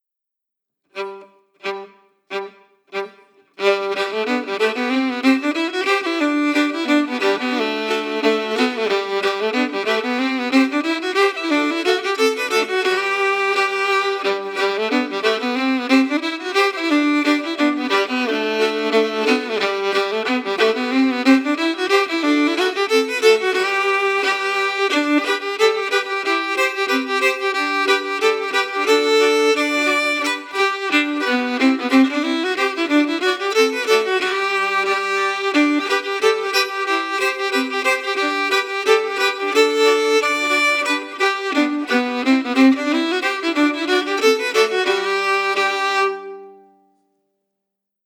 Key: G
Form: Reel
Melody emphasis
Source: Traditional
Region: Appalachia